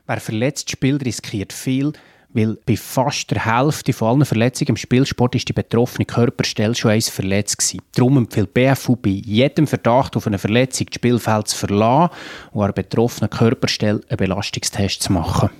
O-Ton zum Download